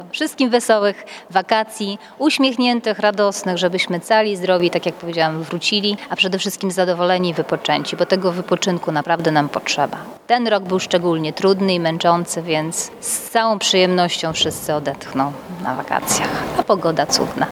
Z mikrofonem i kamerą byliśmy w Szkole Podstawowej nr 3 w Ełku.